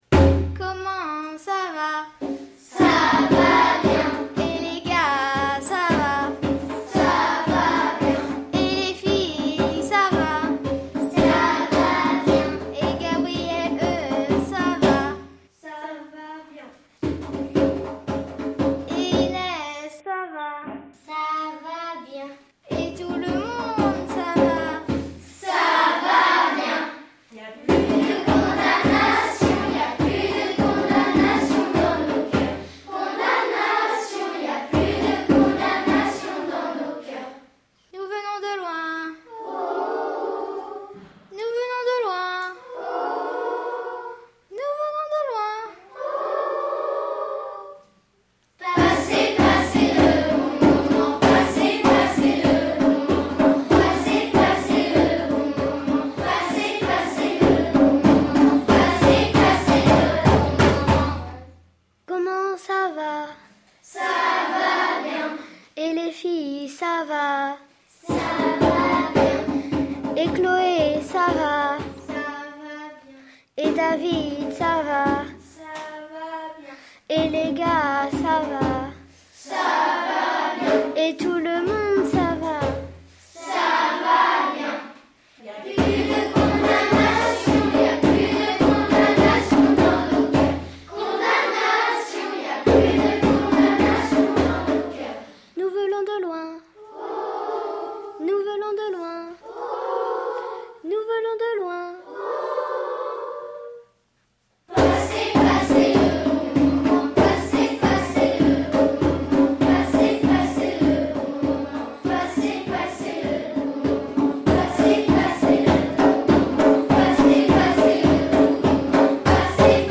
jolies voix de nos CM1 et CM2.